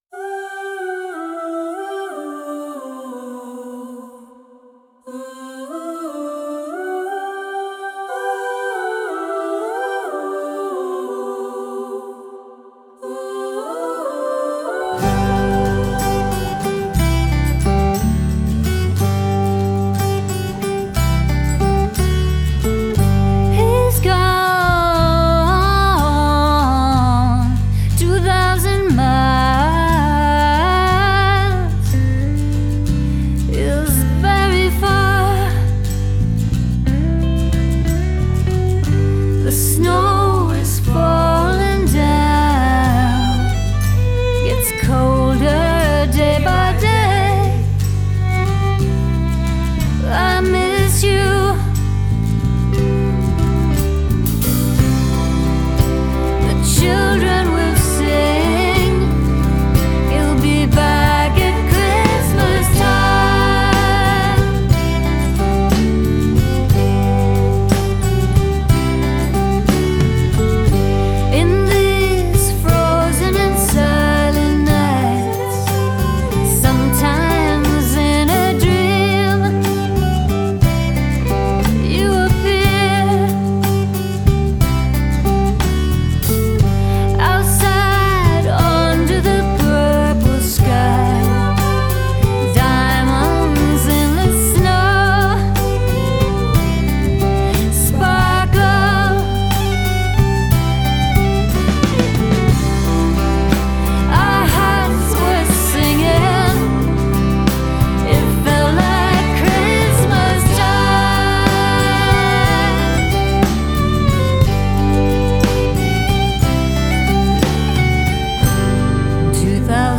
Christmas with a twang.
steel guitar